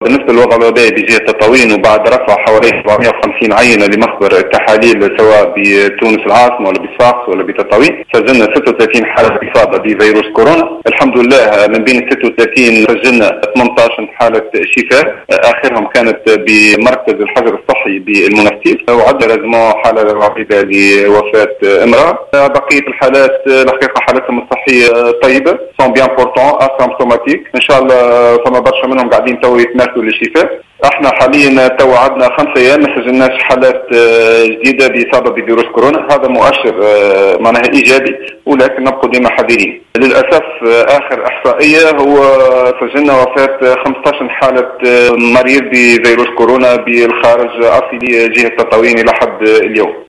أكد المدير الجهوي للصحة بتطاوين، إبراهيم غرغار، في تصريح للجوهرة أف أم، اليوم الأحد، تسجيل 18 حالة شفاء من أصل 36 حالة إصابة بفيروس كورونا في جهة، بعد تعافي أحد المقيمين، أصيلي الجهة في مركز الإيواء بالمنستير.